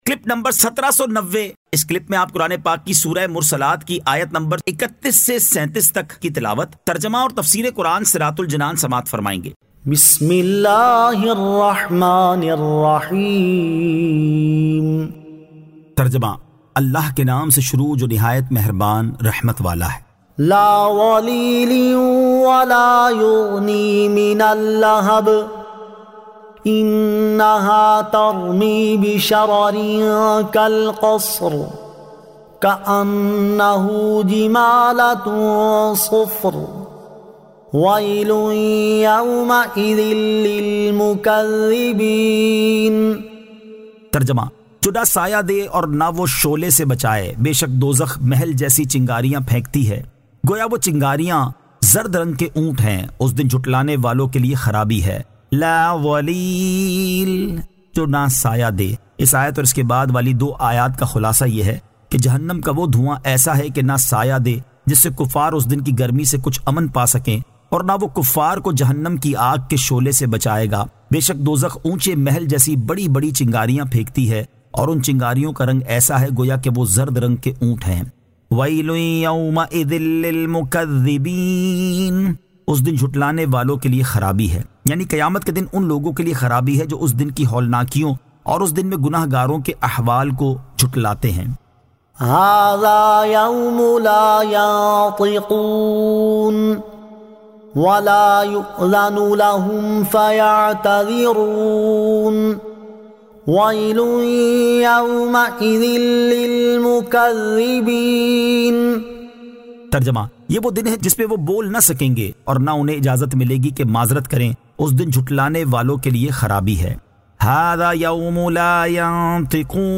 Surah Al-Mursalat 31 To 37 Tilawat , Tarjama , Tafseer